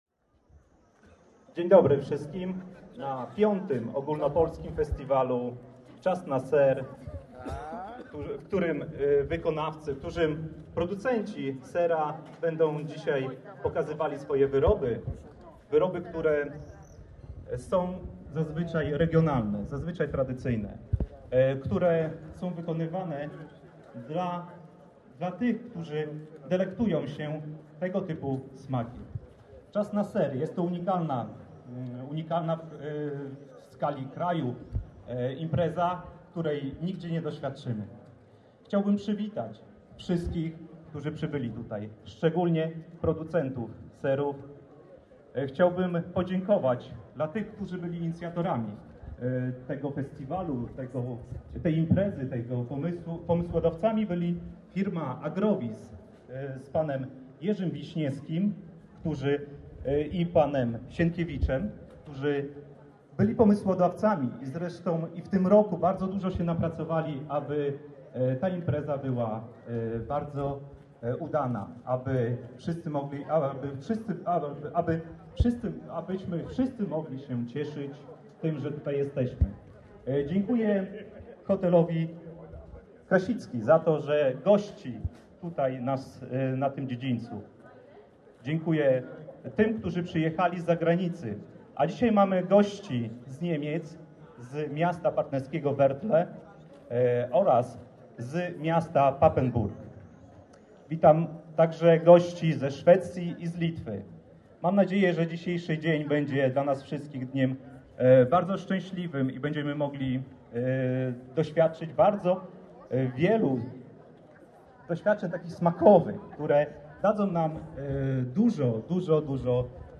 Poniżej nagranie audio z oficjalnego otwarcia imprezy, której dokonał Burmistrz Lidzbarka Warmińskiego pan Jacek Wiśniowski (2 min 26s).
Otwarcie-Czas-na-Ser-2015.mp3